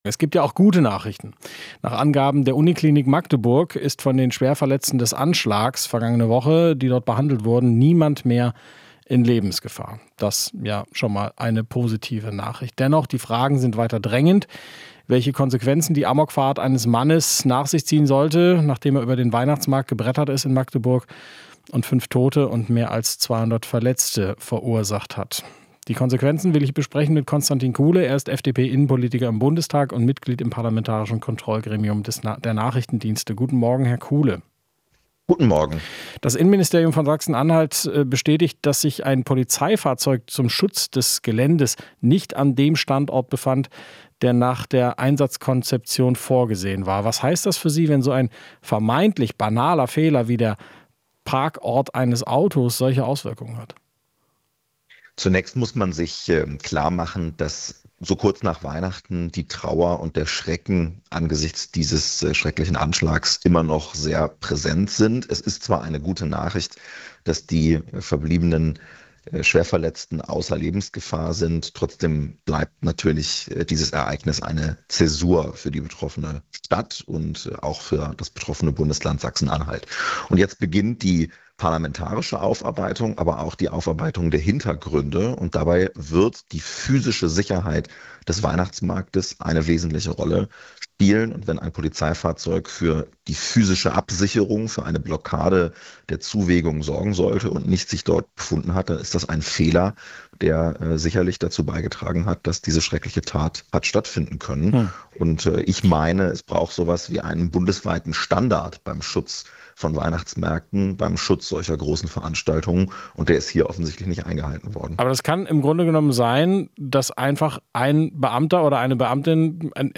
Interview - Kuhle (FDP) fordert bundesweiten Standard für Veranstaltungen